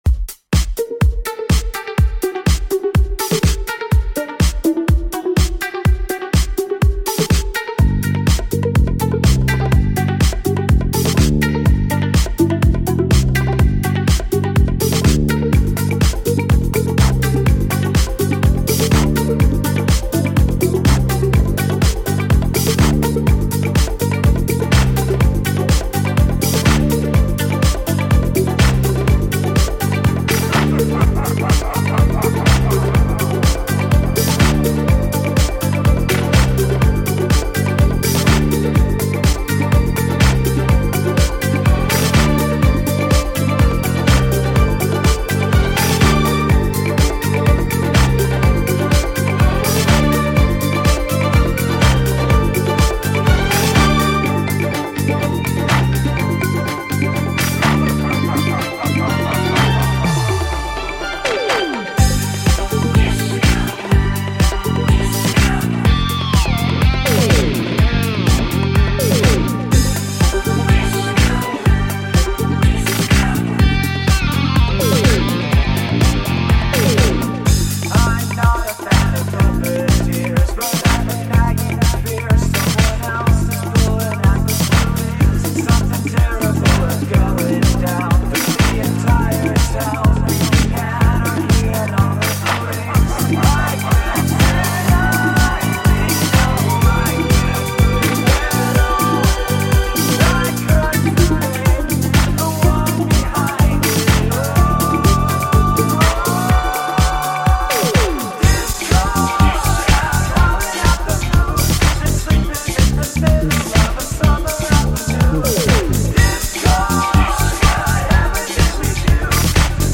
really faithful disco remix